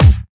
Acoustic Kick Single Shot C# Key 352.wav
Royality free steel kick drum sound tuned to the C# note. Loudest frequency: 532Hz
acoustic-kick-single-shot-c-sharp-key-352-KsA.ogg